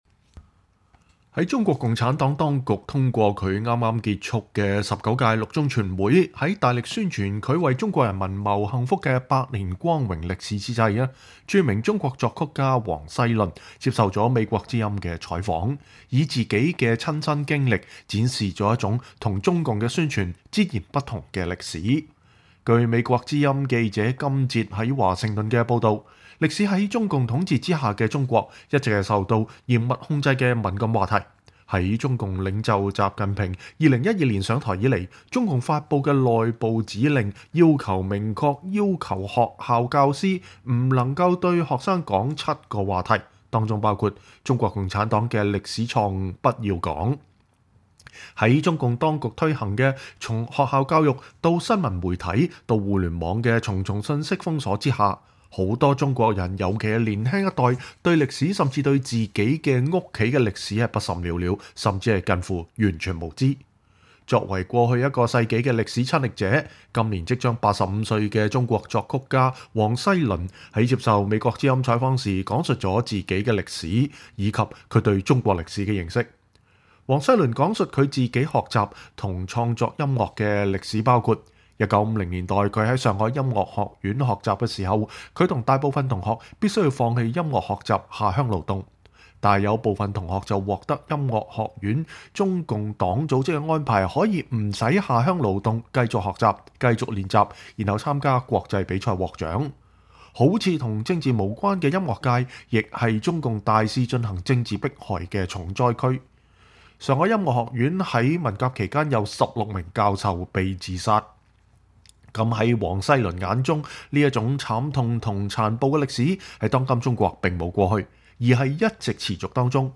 專訪作曲家王西麟(2)： 從音樂看個人與中國的歷史
在中國共產黨當局通過它剛剛結束的十九屆六中全會在大力宣傳它為中國人民謀幸福的百年光榮歷史之際，著名中國作曲家王西麟接受美國之音採訪，以自己的親身經歷展示了一種跟中共的宣傳截然不同的歷史。